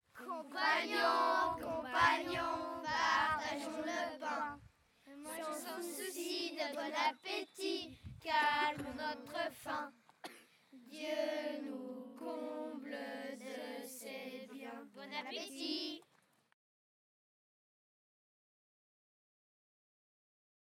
Genre : chant
Type : chant de mouvement de jeunesse
Interprète(s) : Patro de Pontaury
Lieu d'enregistrement : Florennes
Chanté avant le repas.